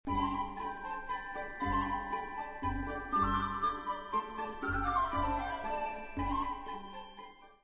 Īstā Mūzika MP3 formātā, kāda tā skan patiesībā